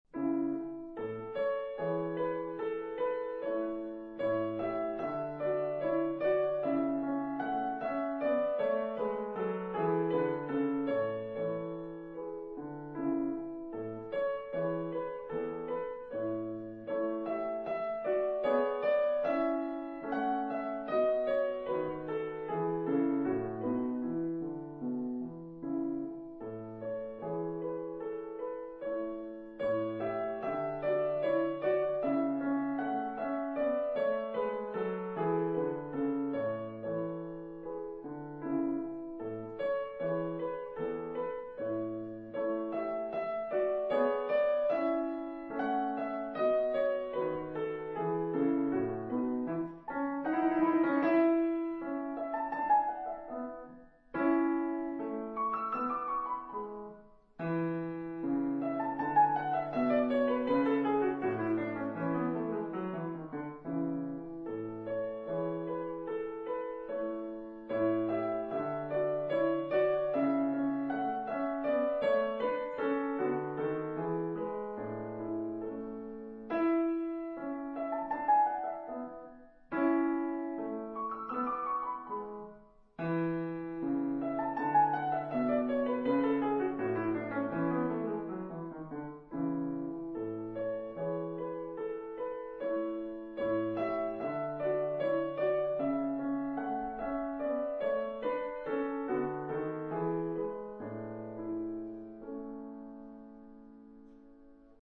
像跳舞、像漫步，又像冥想。
音色乾淨，絲毫不見俗氣，更沒有不必要的華麗與過度的歌唱性。